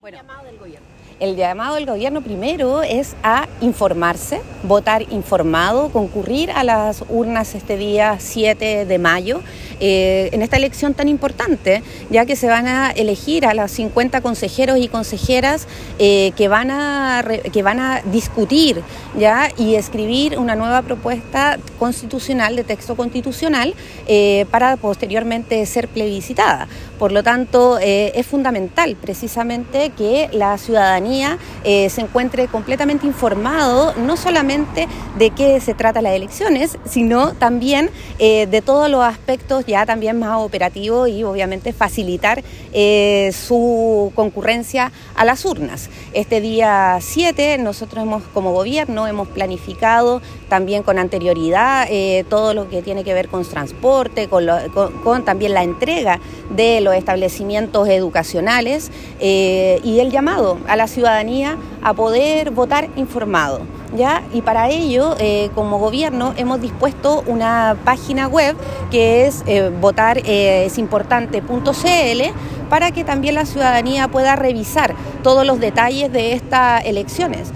Cuna-seremi-de-Gobierno-Paulina-Mora-elecciones-07-05.m4a